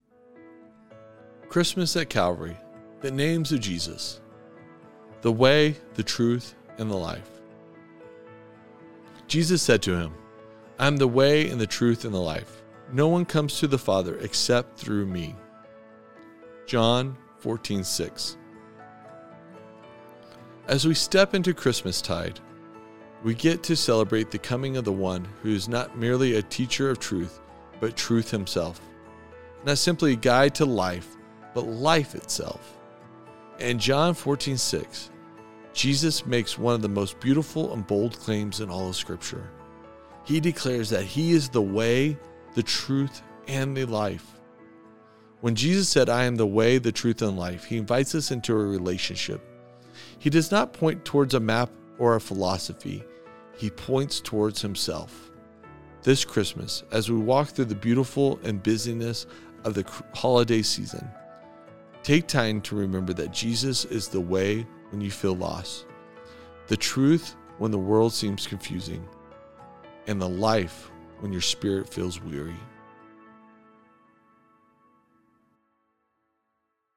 Advent Readings & Prayer